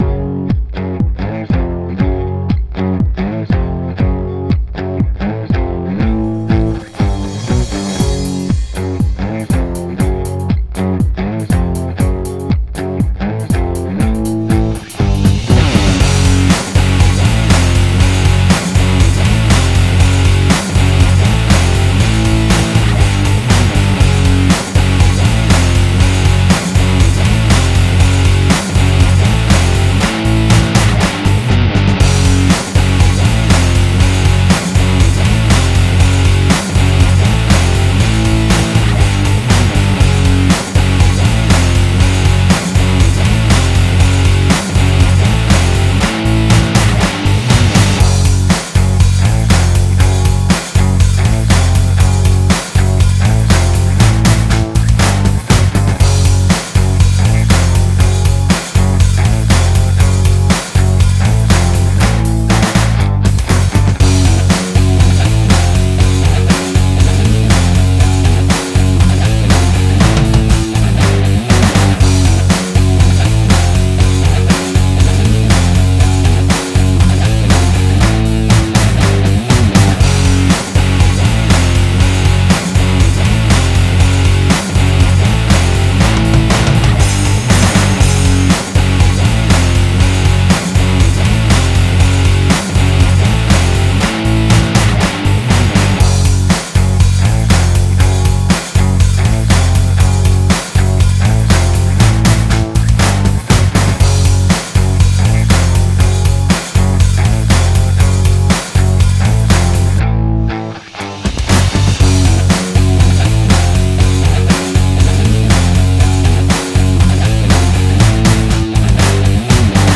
Minus Tracks, Music, Samples, Loops